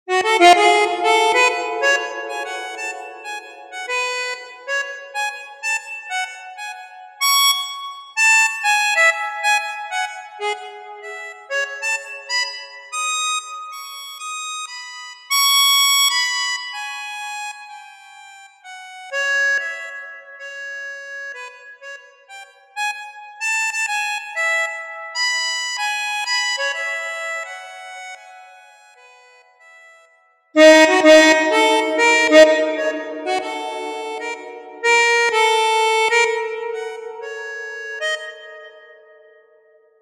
Harmonica Solo